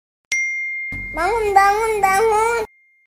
Kategori: Nada dering
Nada notifikasi ini pas buat kamu yang suka dengar suara bayi lucu.